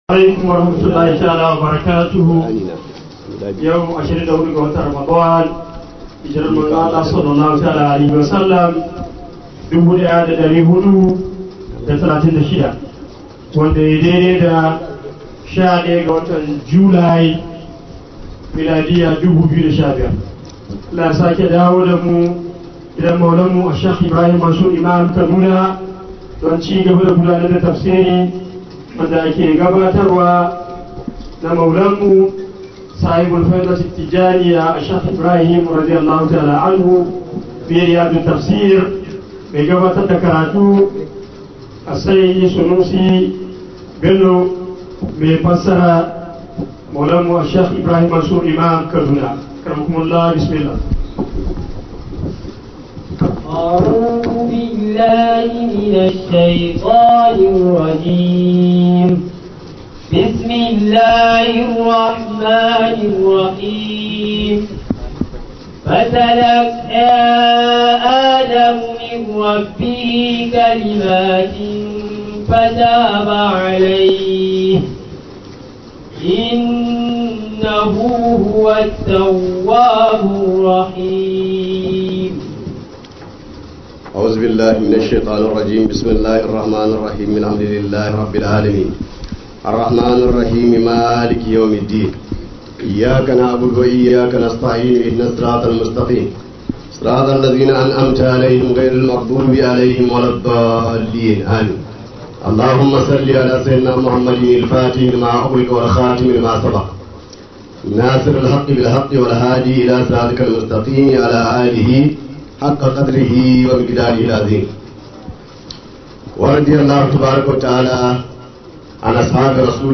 017 HAYATUDEEN ISLAMIC STUDIO TAFSIR 2015.mp3
Fityanumedia Audios is a platform dedicated to sharing audio files of lectures from renowned Islamic scholars.